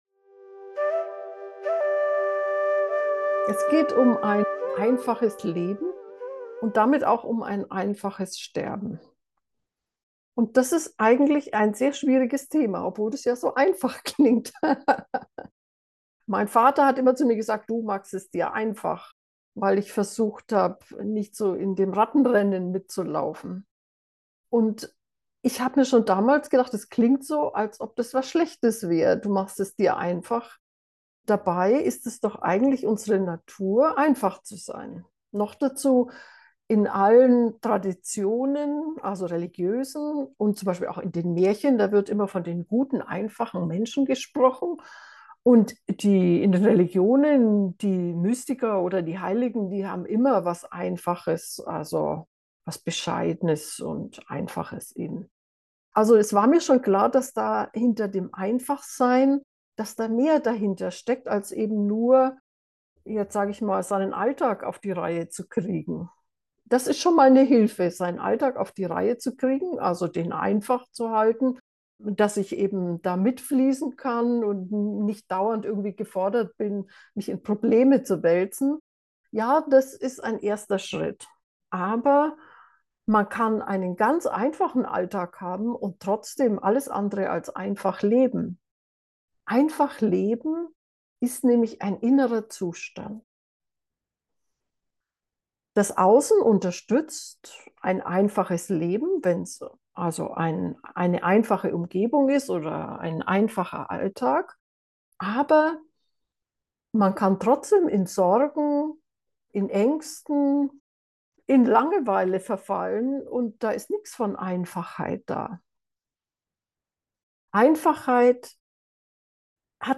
Wenn du auch ein einfaches Leben suchst, vielleicht inmitten deines Alltags, dann lass dich in dieser geführten Meditation nach innen führen. Dort ist die Basis für Einfachheit und auf die kannst du dich noch im Sterben verlassen.